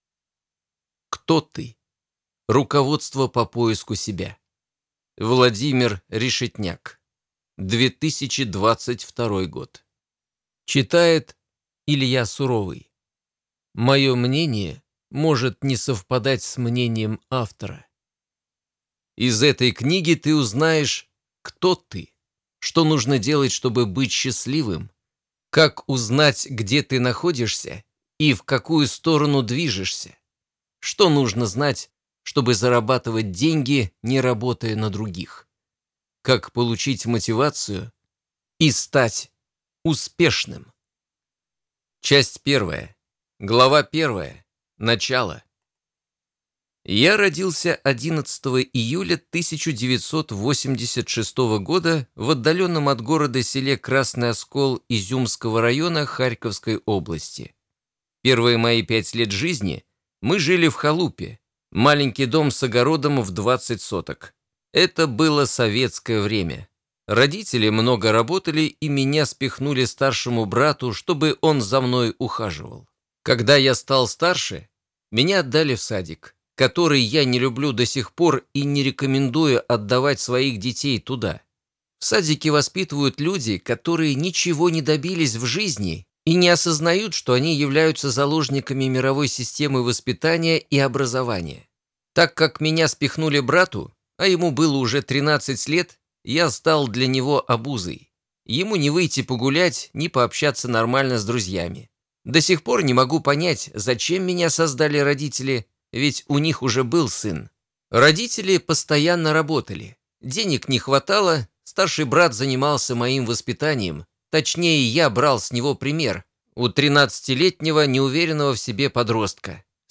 Аудиокнига Кто ты?